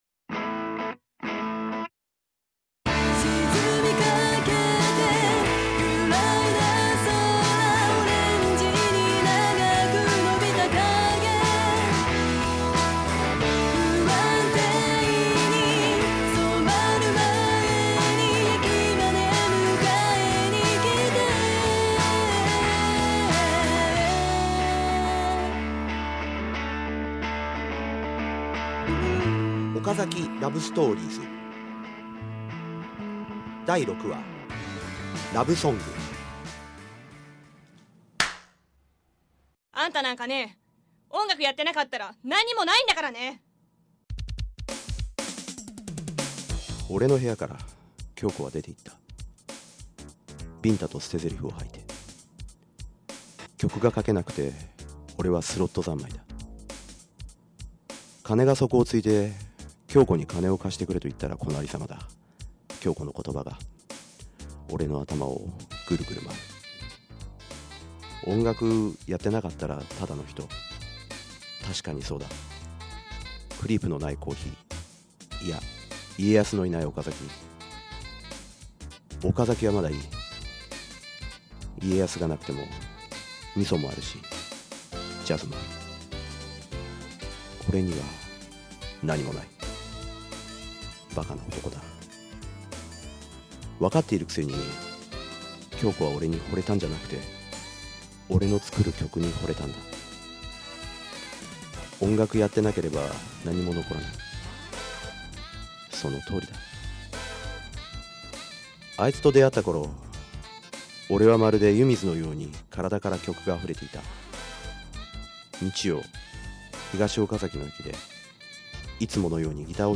ギター